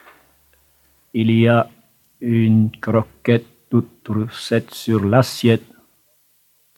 Genre : chant
Type : chanson à poter
Interprète(s) : Anonyme (homme)
Support : bande magnétique
"Comptine." (Note du collecteur)